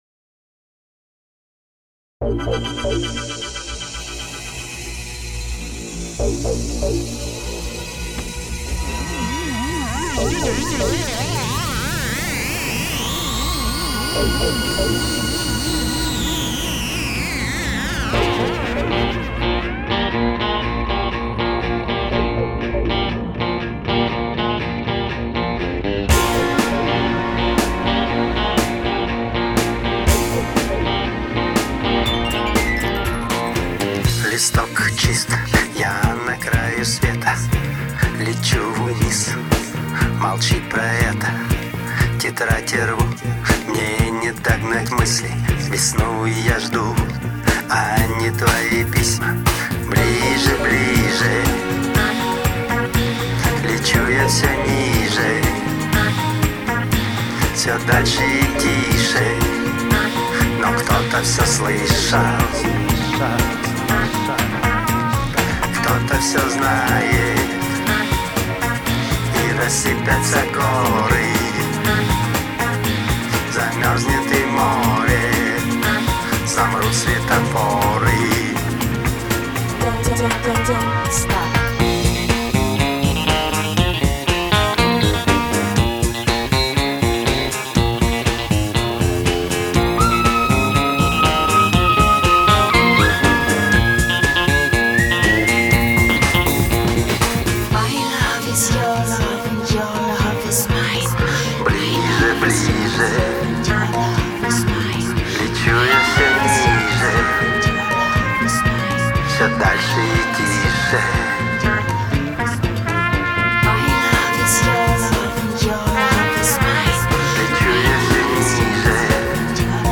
mp3,5736k] Рок